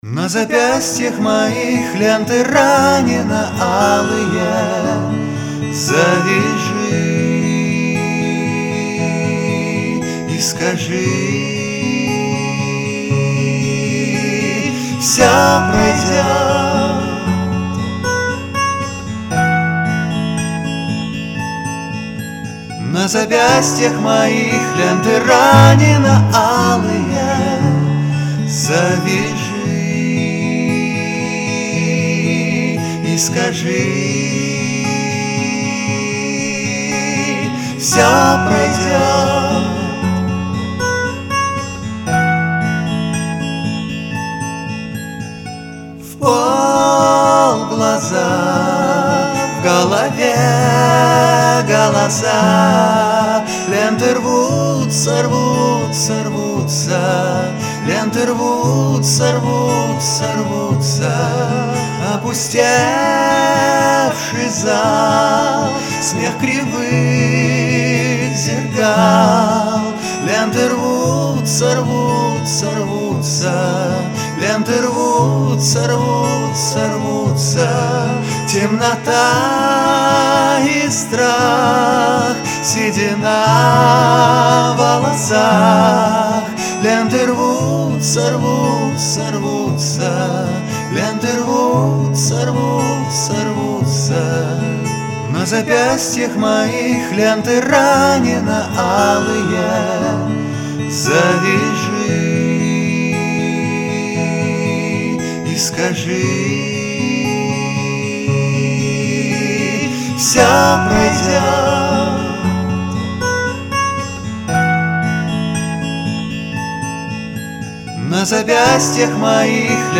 Все треки записаны на домашней студии.